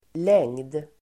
Uttal: [leng:d]